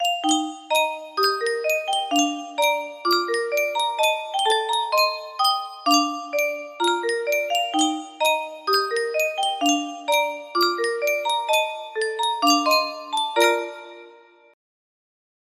Yunsheng Music Box - Aloha Oe Y443 music box melody
Full range 60